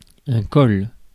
Ääntäminen
France: IPA: [ɛ̃ kɔl]